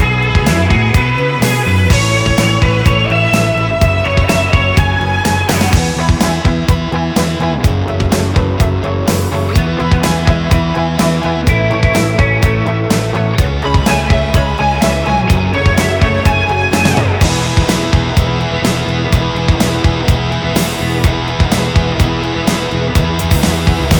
Minus Main Guitar Pop (1980s) 3:45 Buy £1.50